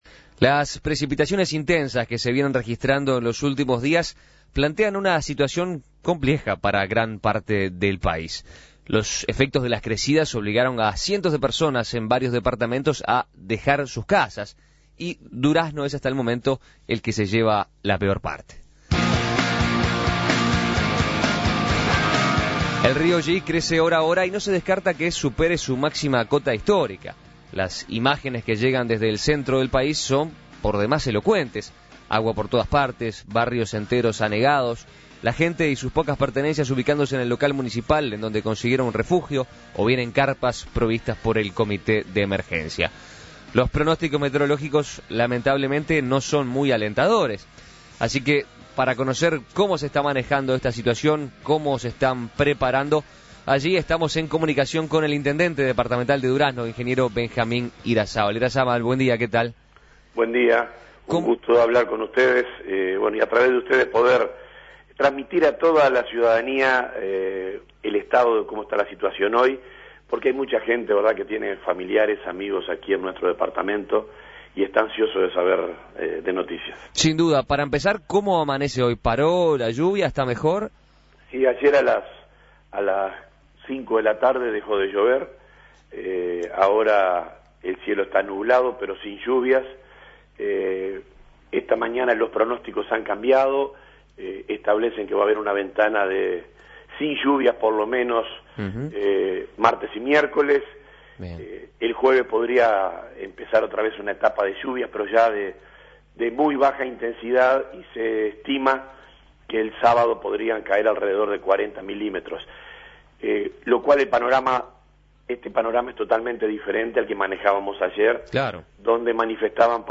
Durazno es el que se ha llevado la pero parte, con más de 1.000 evacuados. Su intendente, Benjamín Irazábal, dijo a En Perspectiva que la población respondió muy bien ante el estado de alerta que vivía el departamento, evacuándose por sus propios medios o a través del comité.